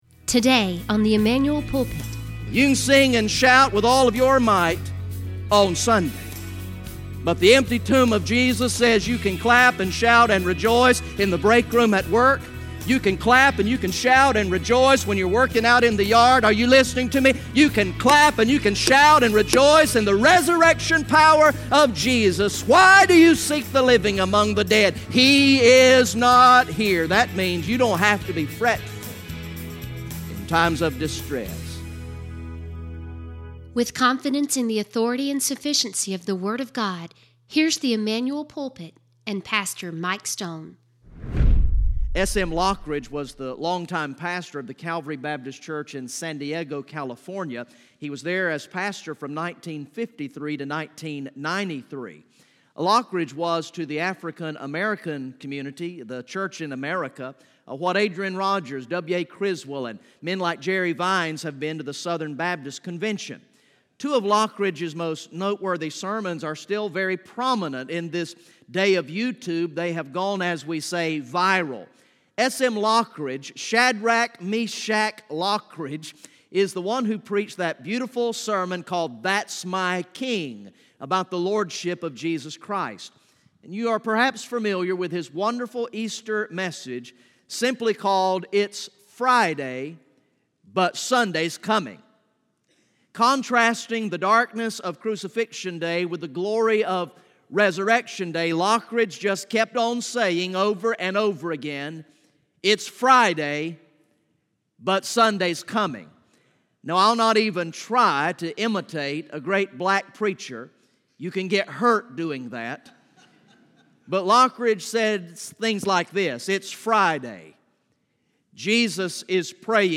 From the evening worship service on Sunday, April 1, 2018